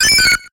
Grito de Wooper.ogg
Grito_de_Wooper.ogg.mp3